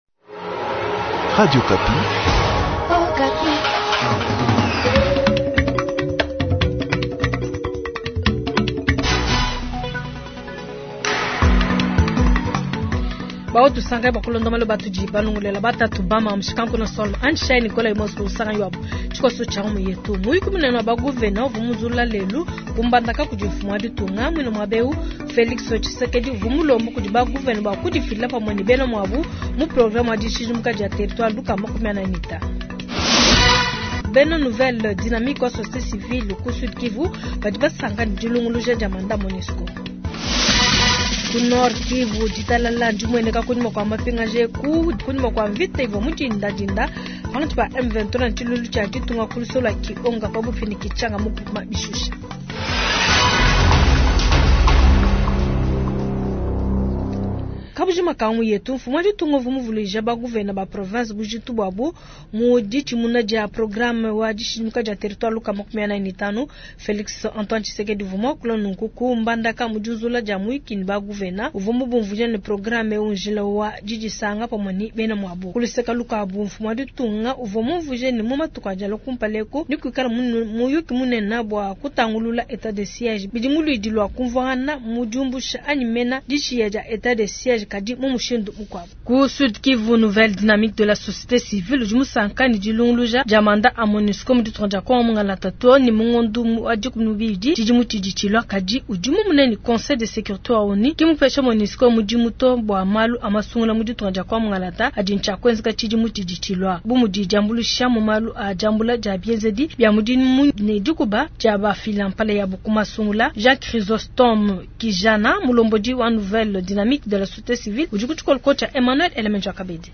Jounal soir